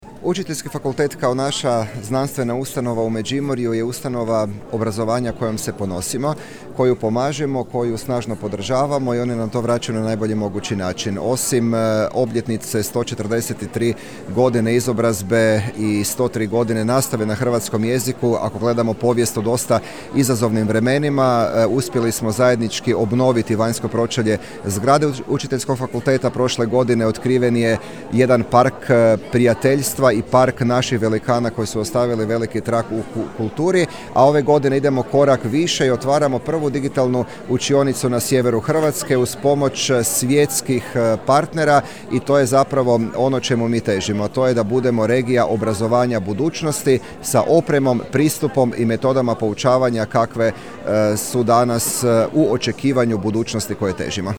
U nju je kroz javno-privatno partnerstvo uloženo 200 tisuća kuna i nastavak je ulaganja u digitalizaciju Učiteljskog fakulteta, moglo se čuti na prigodnoj svečanosti koja je bila i prilika za podjelu zahvalnica.
Matija Posavec, međimurski župan: